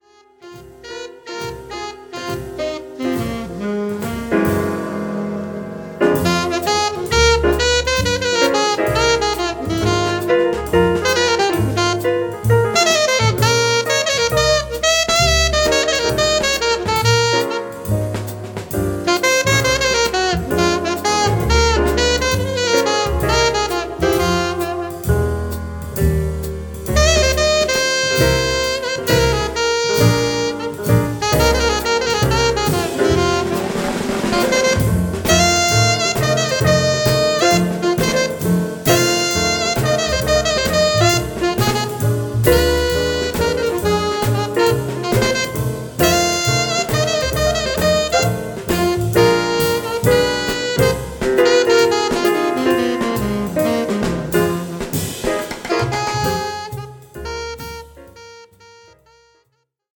ホーム ｜ JAZZ / JAZZ FUNK / FUSION > JAZZ